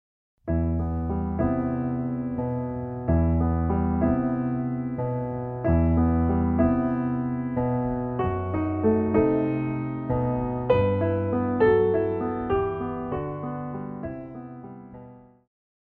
古典,流行
鋼琴
演奏曲
世界音樂
僅伴奏
沒有主奏
沒有節拍器